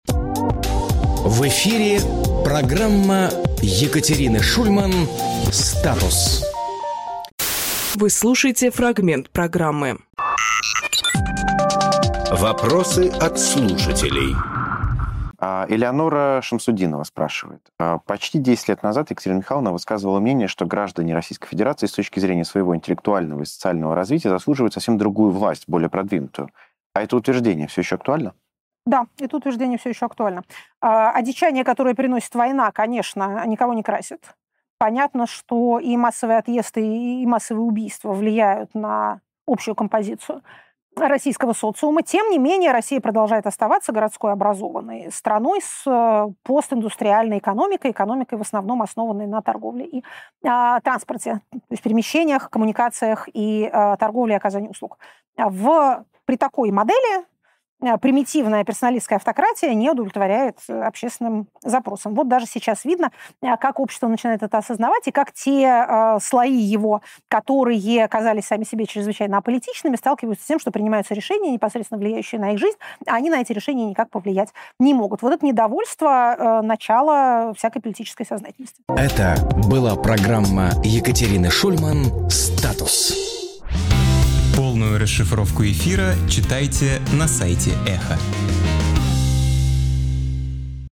Екатерина Шульманполитолог
Фрагмент эфира от 31.03.26